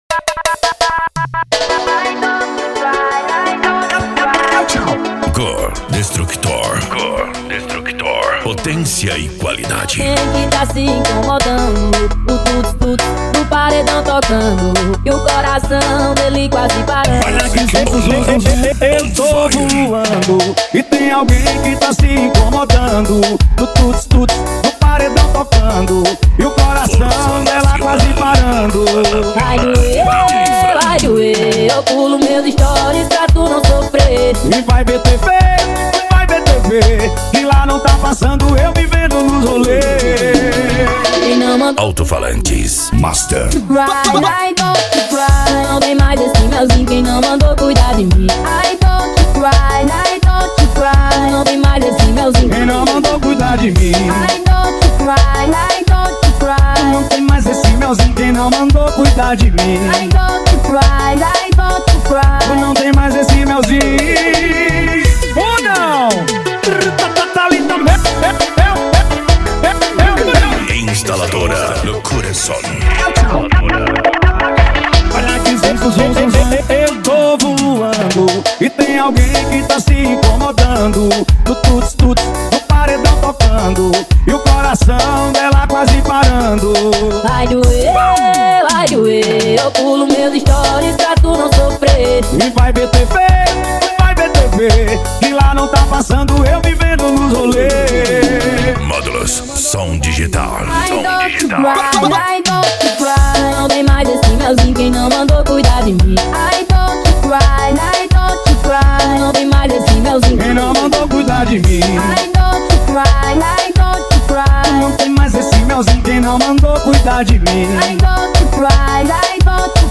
Bass
Funk
Remix